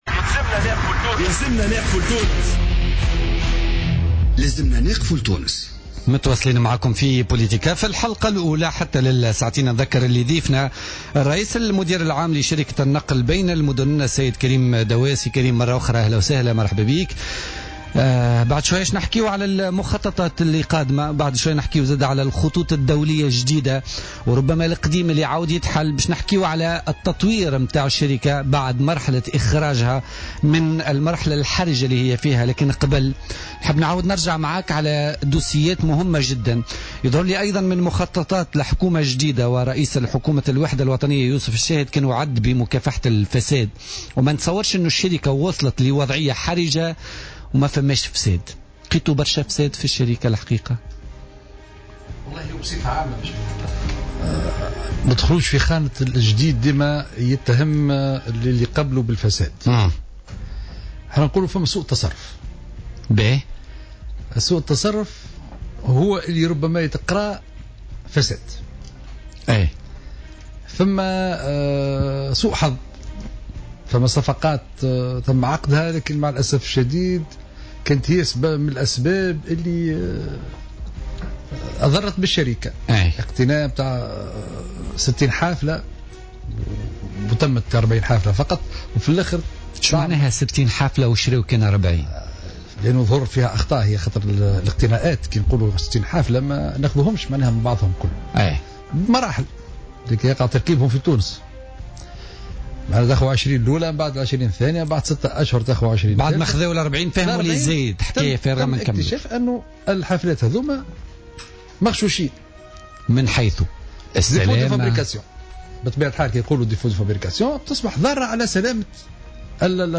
sur les ondes de Jawhara Fm